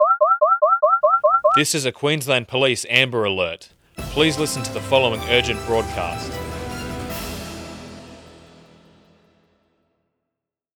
퀸즐랜드 경찰의 앰버 경고 효과음